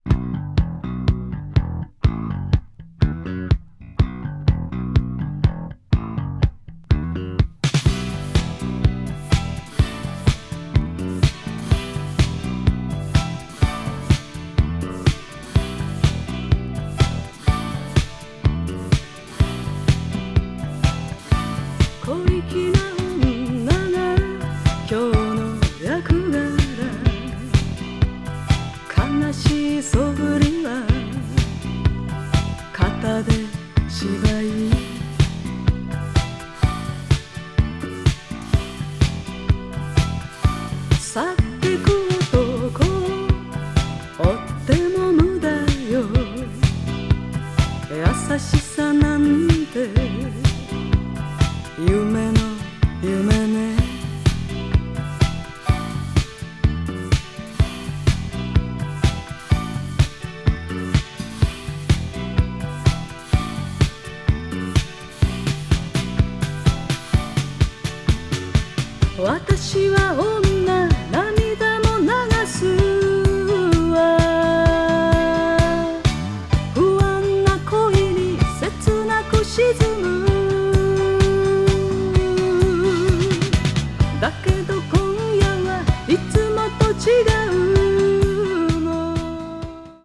adult pop   japanese pop   kayohkyoku   mellow groove